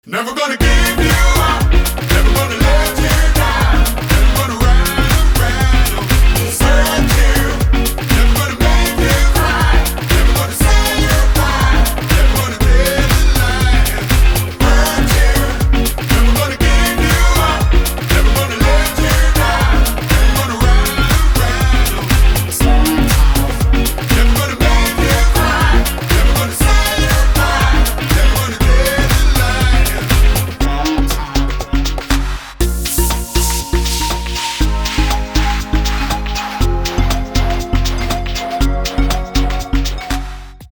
• Качество: 320, Stereo
мужской вокал
женский голос
dance
house